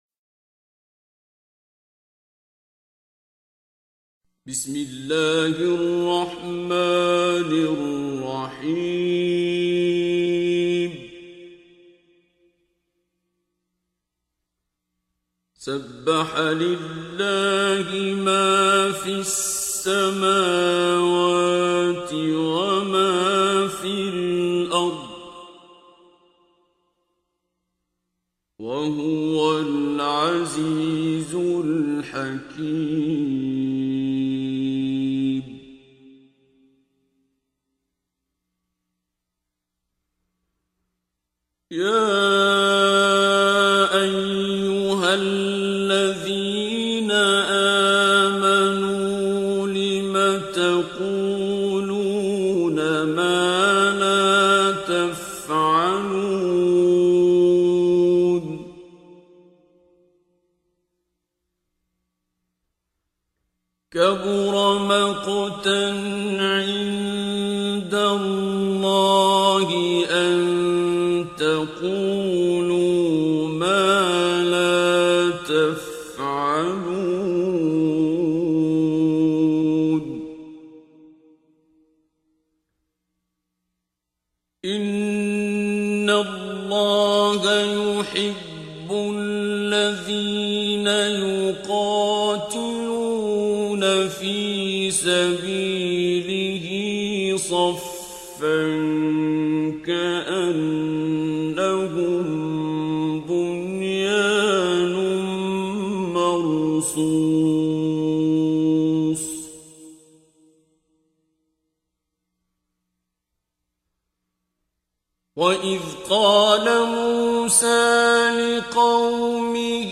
دانلود تلاوت زیبای سوره صف آیات 1 الی 14 با صدای دلنشین شیخ عبدالباسط عبدالصمد
در این بخش از ضیاءالصالحین، تلاوت زیبای آیات 1 الی 14 سوره مبارکه صف را با صدای دلنشین استاد شیخ عبدالباسط عبدالصمد به مدت 11 دقیقه با علاقه مندان به اشتراک می گذاریم.